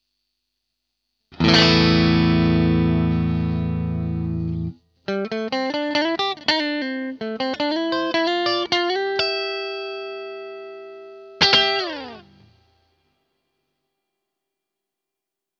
GT -1000coreとGX-1の同じアンプタイプにうっすらホールリバーブをかけた音でサンプルを録ってみました。
いずれもアンプシミュレーターとリバーブのみの音色です。
GX-1 クリーン（アンプタイプ　NATURAL）